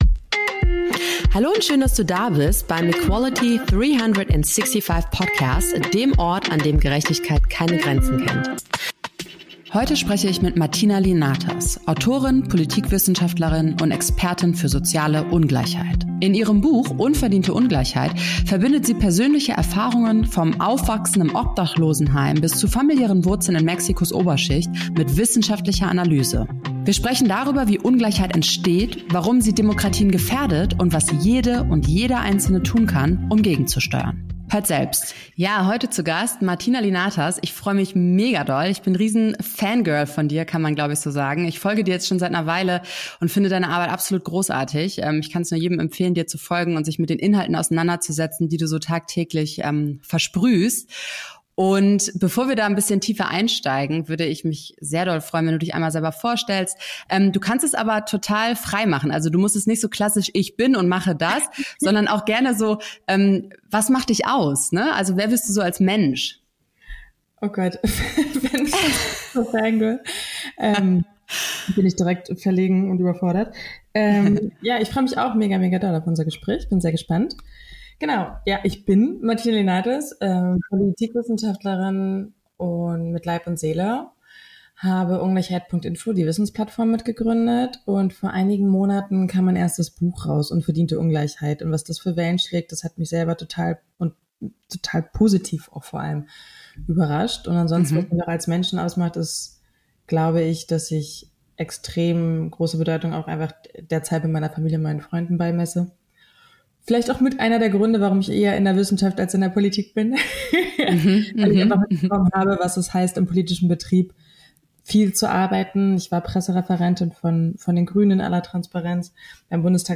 Ein Gespräch über Macht, Chancen und den Mut, Solidarität wieder politisch zu denken. Oder anders gesagt: über die Frage, wie gerecht Deutschland wirklich ist.